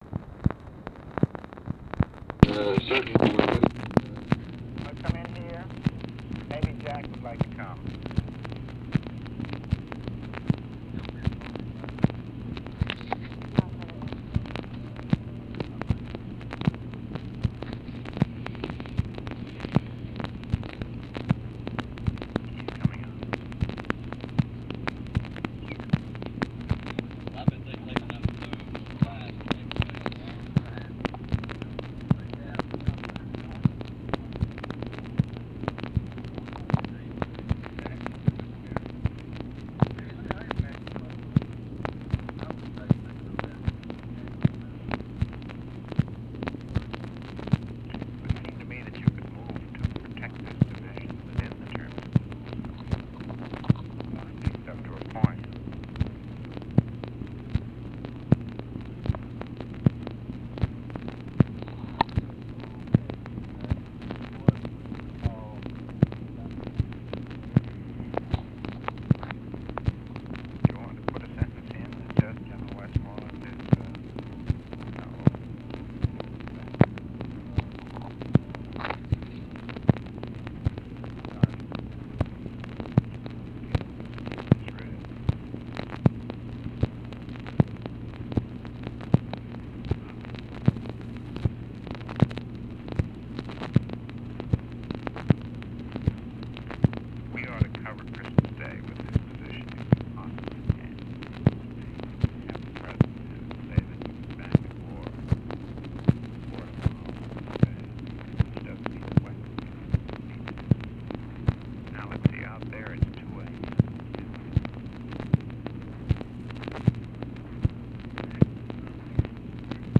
Conversation with MCGEORGE BUNDY and OFFICE CONVERSATION, December 25, 1965
Secret White House Tapes